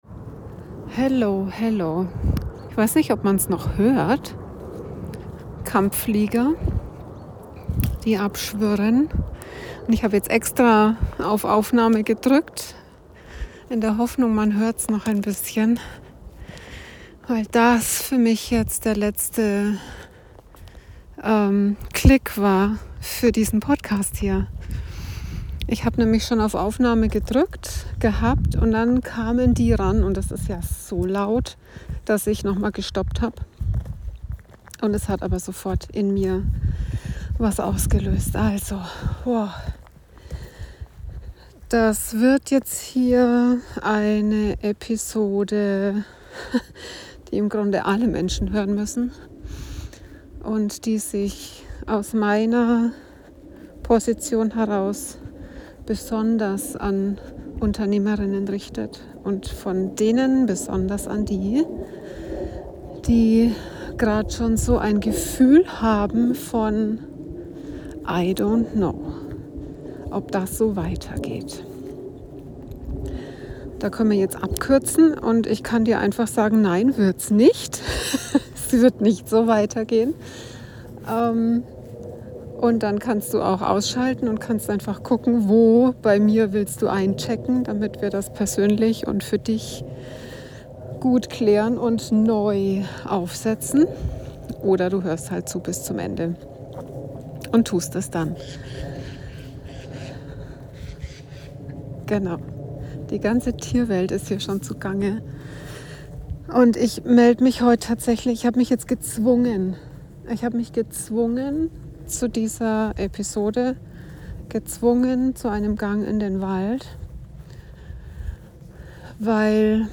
Live aus meinem aktuellen, sehr persönlichen ZwischenRaum. Es ist spontan im Wald eine kleine MeisterKlasse geworden, die für alle Menschen relevant ist.